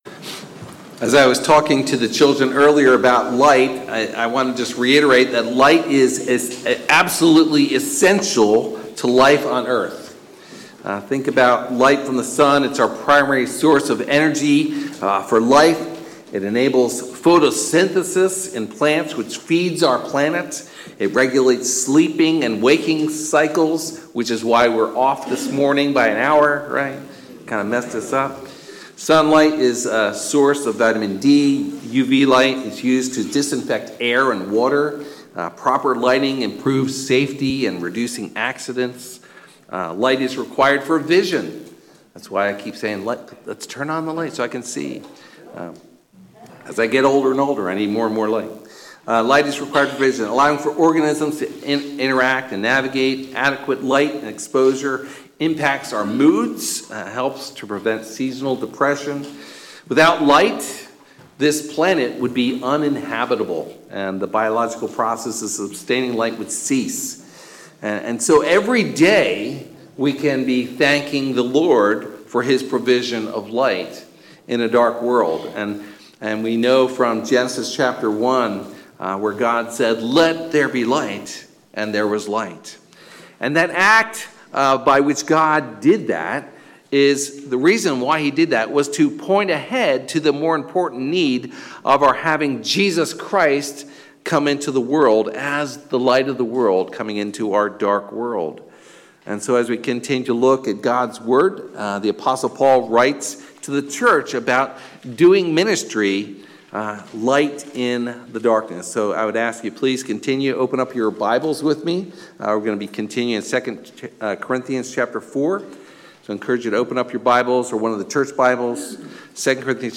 Sermon Audio
Sermons from Crossroads Community Church: Upper Darby, PA